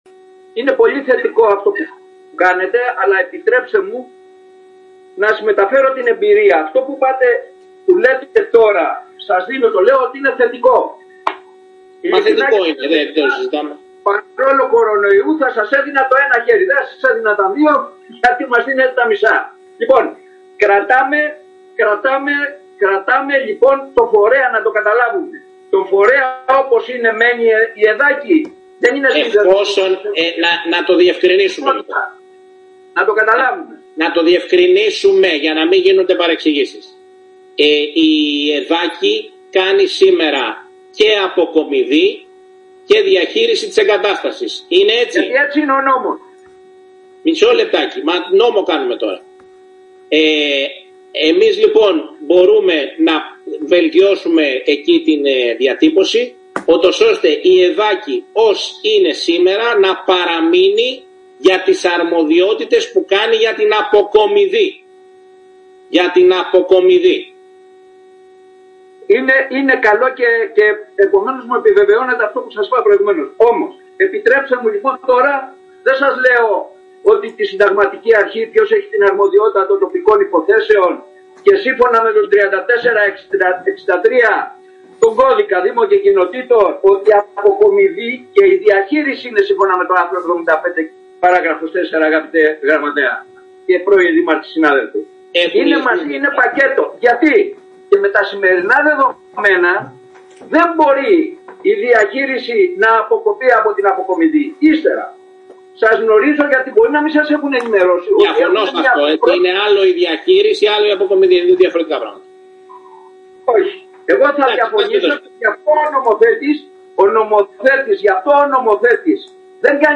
συζήτηση – ομιλία